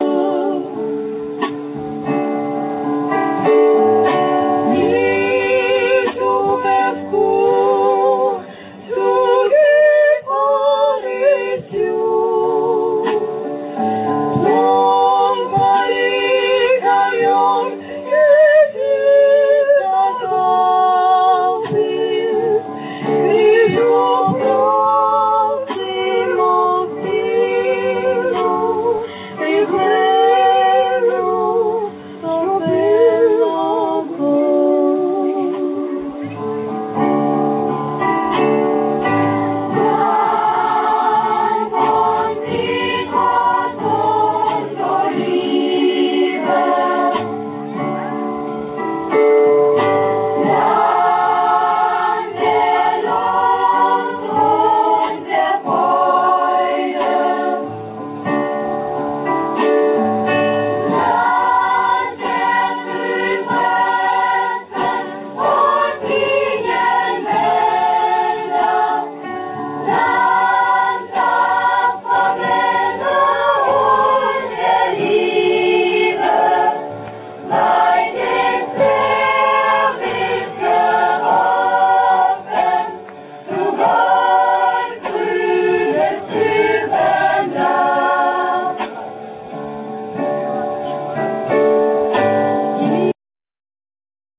Συνεργασία νεανικής ορχήστρας  κιθάρων από το Αίγιον
και   χορωδίας νέων από το Ηannover υπο την
με ελληνικές μελωδίες να τραγουδιώνται στα ελληνικά